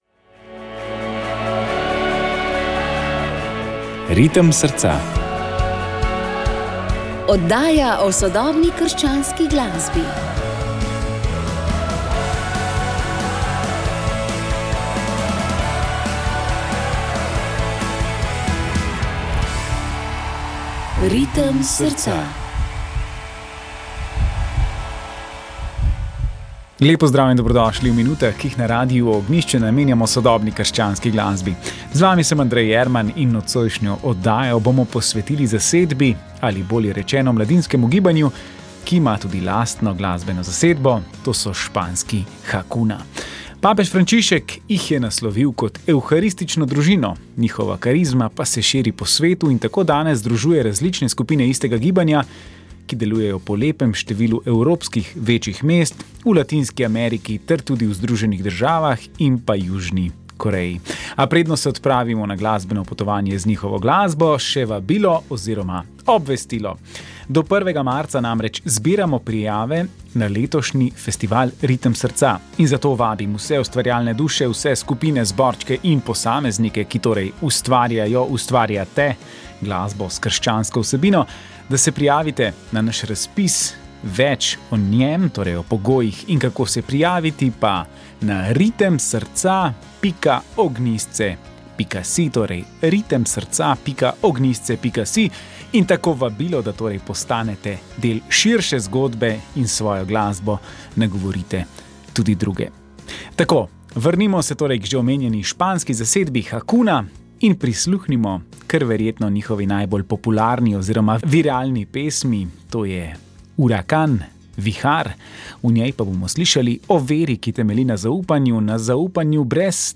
Njegovo pričevanje v dveh delih je bilo posneto v letih 1978 in 1979. Dosegljivo v Arhivu Republike Slovenije.